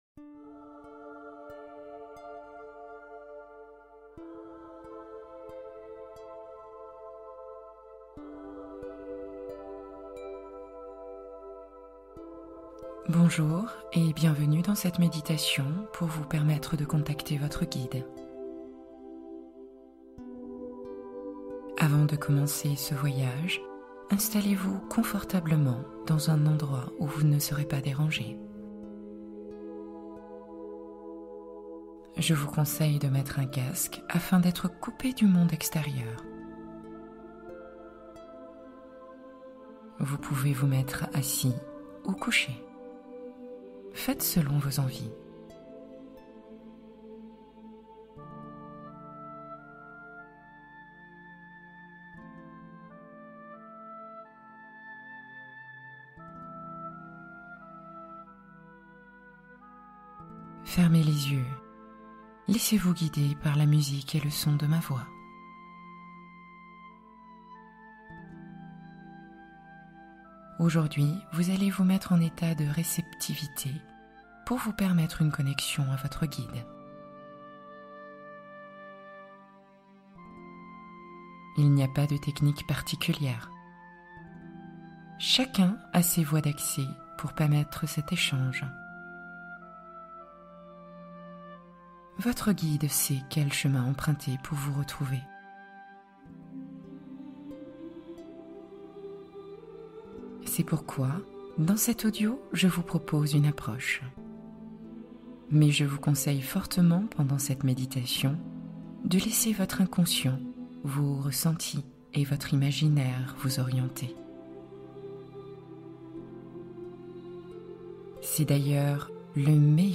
Contacter son guide - Méditation guidée